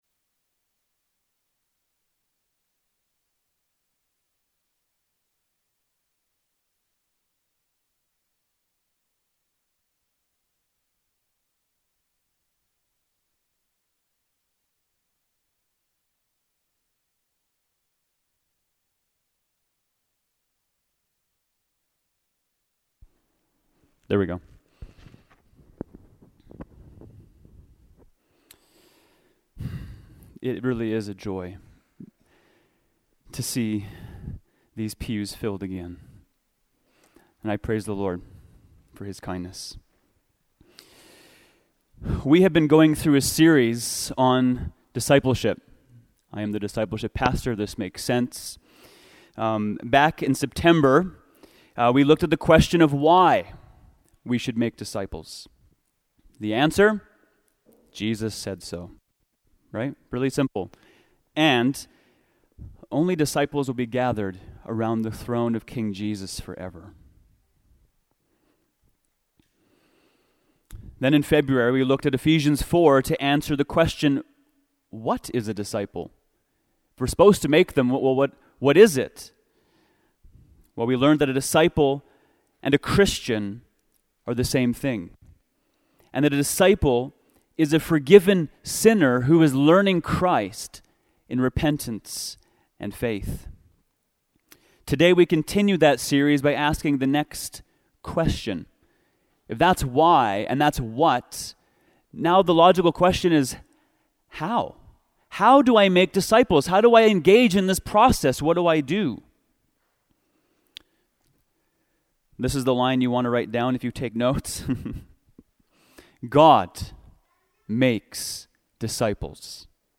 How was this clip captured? Watch here the full service